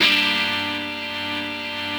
rockerChordGm.wav